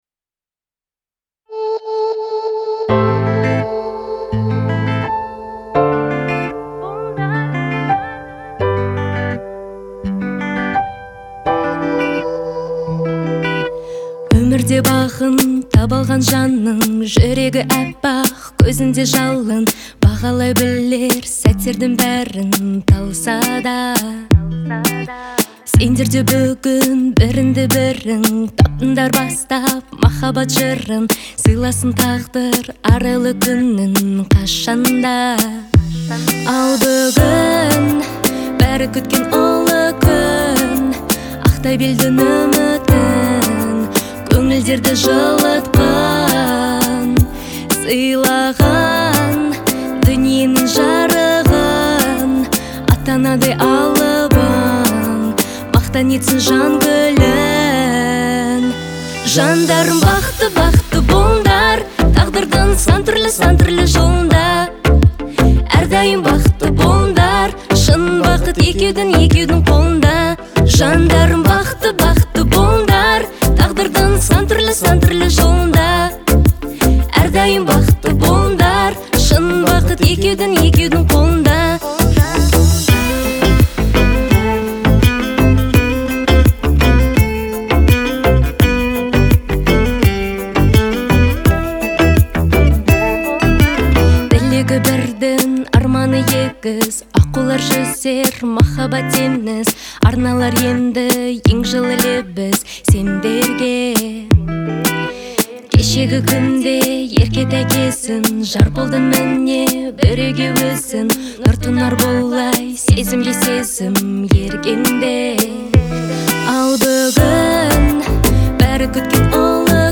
это яркая и мелодичная песня в жанре казахской поп-музыки
Настроение композиции наполнено радостью и оптимизмом.
Звучание песни отличается легкостью и запоминающимся мотивом
теплый голос